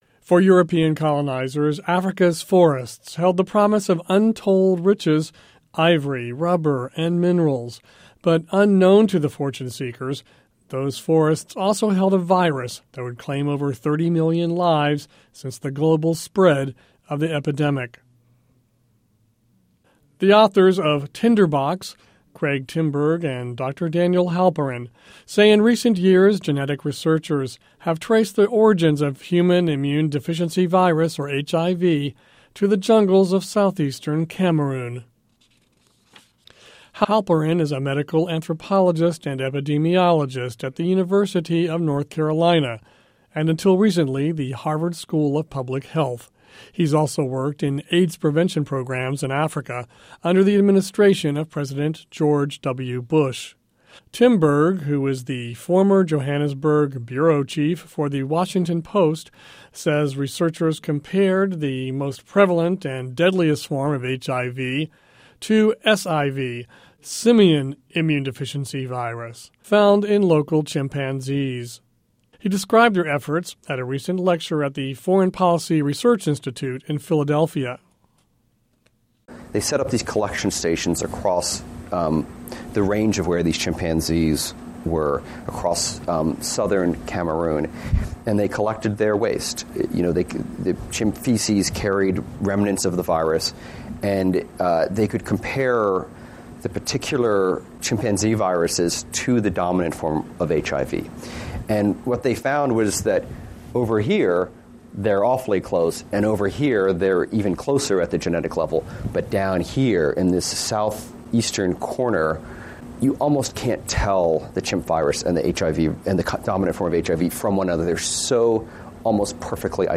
listen to report on "Tinderbox..." - a look at colonization and HIV/AIDS